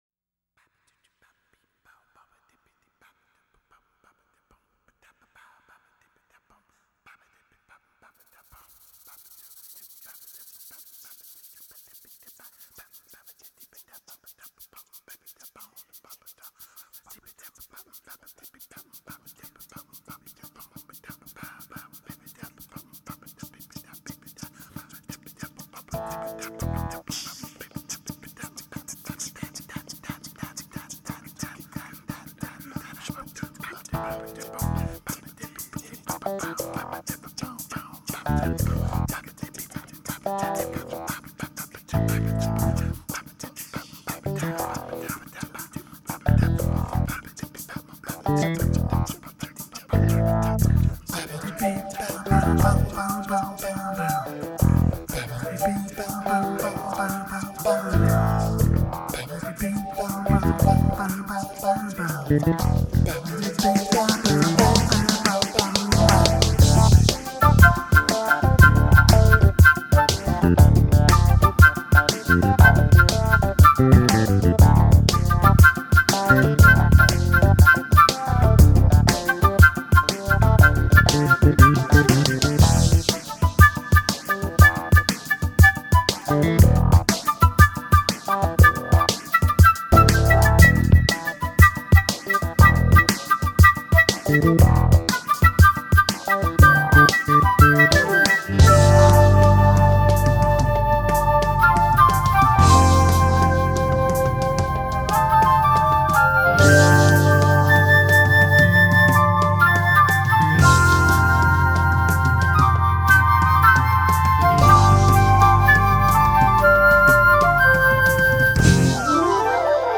Experimental R&B